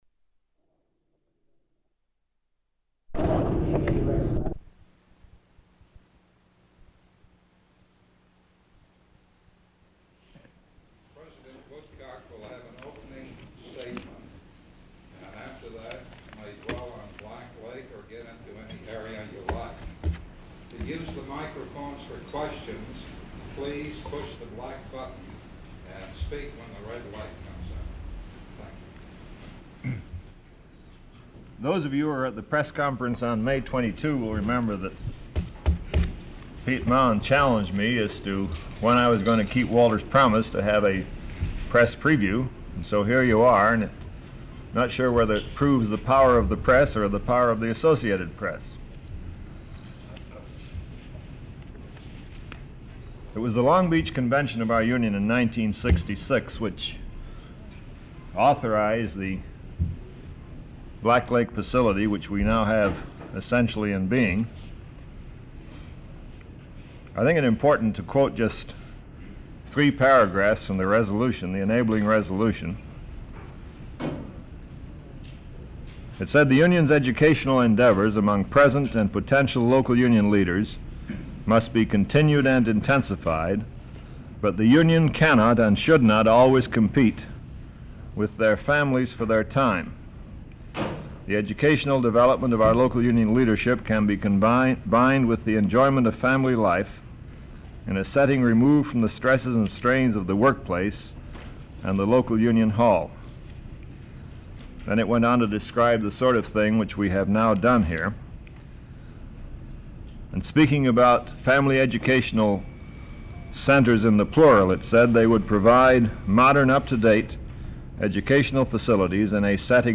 Walter P. Reuther Digital Archive · UAW President Leonard Woodcock - Press Conference before United Nations Conference took place - Black Lake, Family Education Center, Reel 1 · Omeka S Multi-Repository